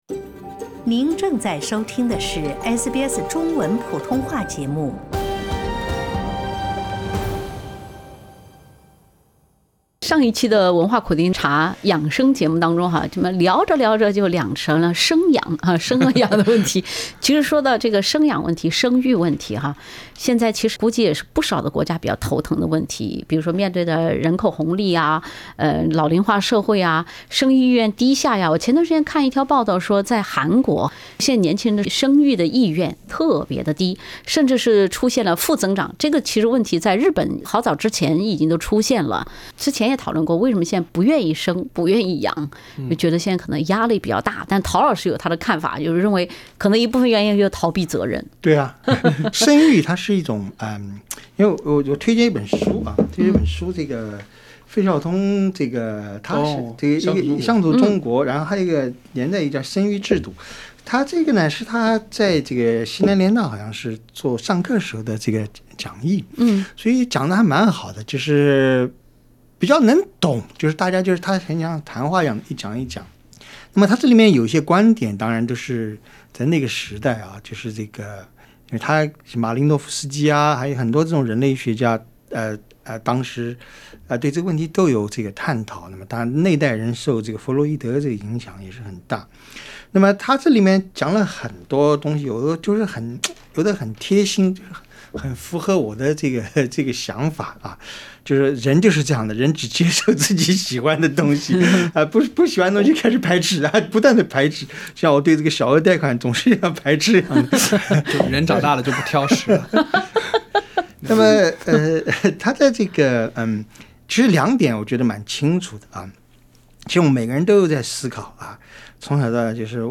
欢迎收听SBS 文化时评栏目《文化苦丁茶》，本期话题是：都不生孩子，养老怎么办？